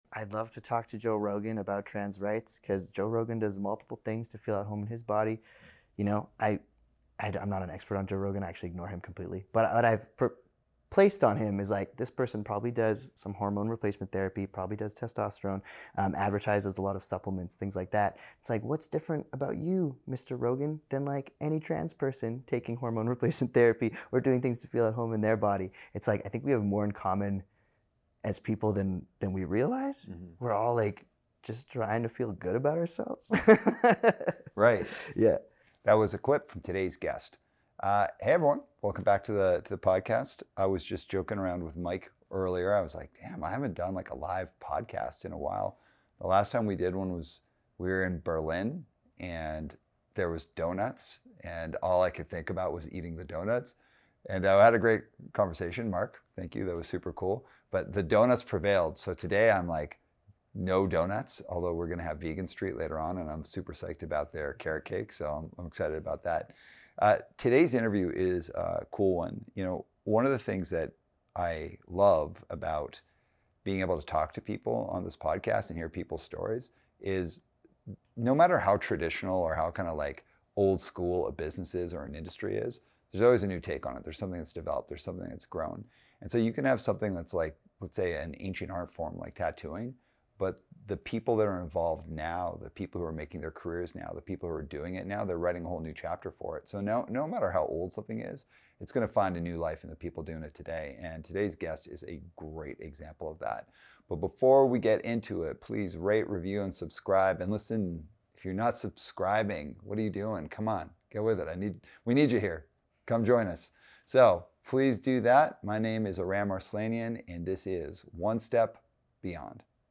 Tattooer & Burlesque Performer